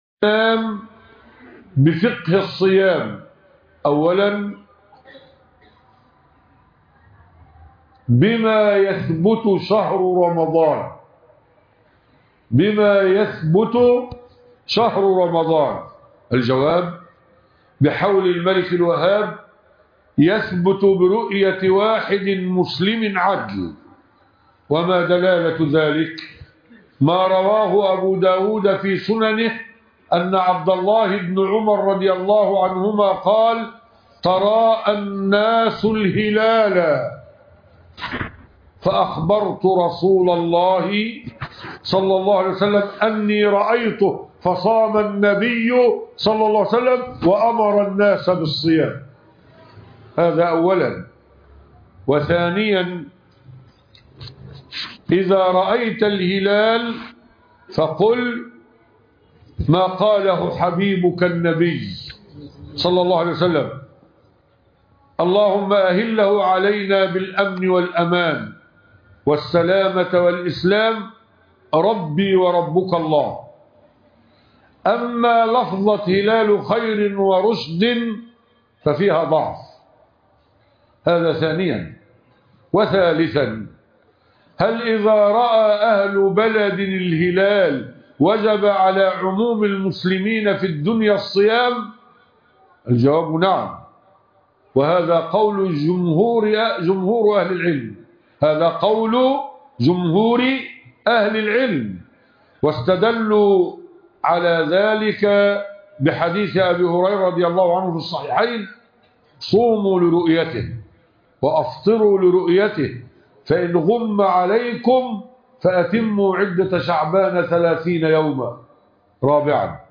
خطبة الجمعه حول شهر رمضان